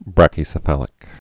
(brăkĭ-sə-fălĭk) also brach·y·ceph·a·lous (-sĕfə-ləs)